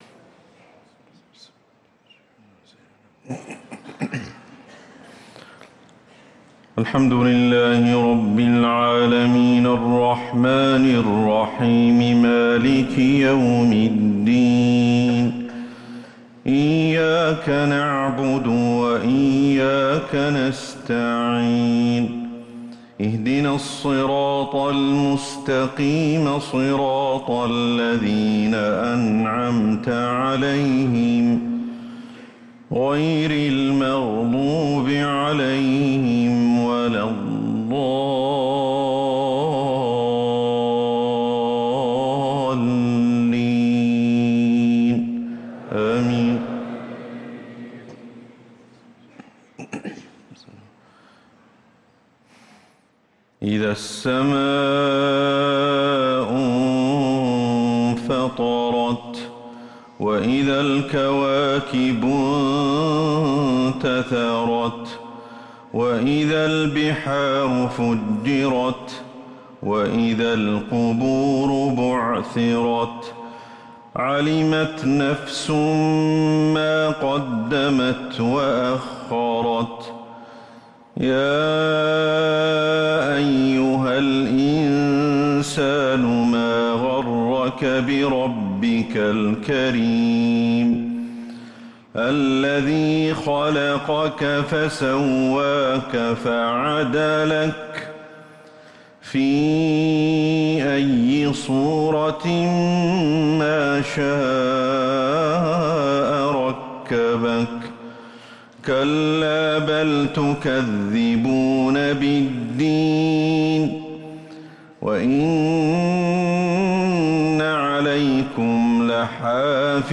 صلاة العشاء للقارئ أحمد الحذيفي 12 محرم 1445 هـ
تِلَاوَات الْحَرَمَيْن .